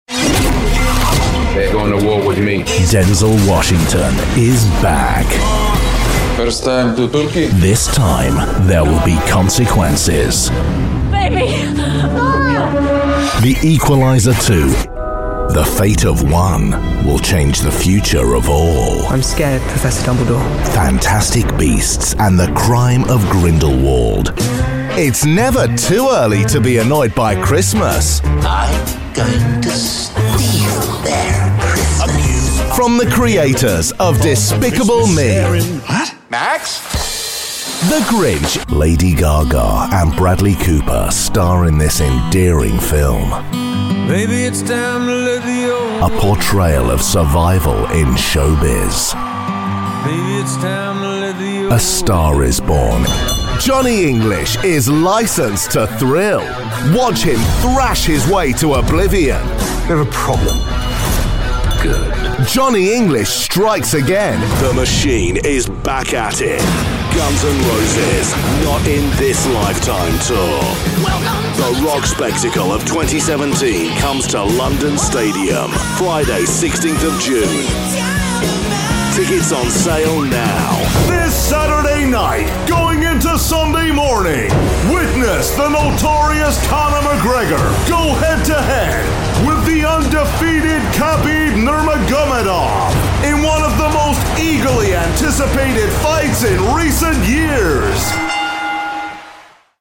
English (British)
Promos
Mic: Neumann U87